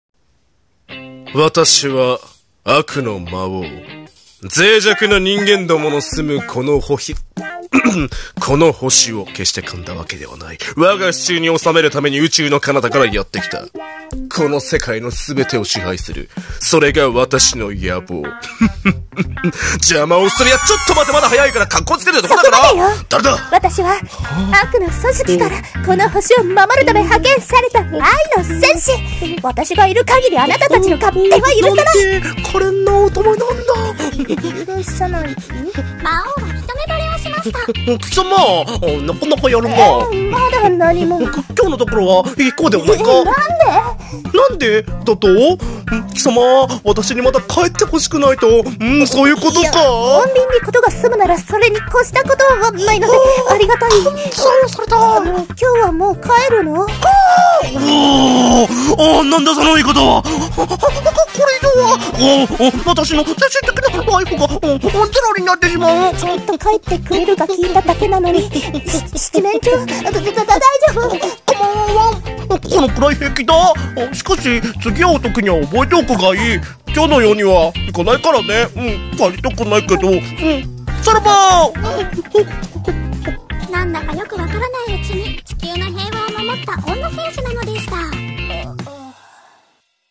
【声劇】魔王vs女戦士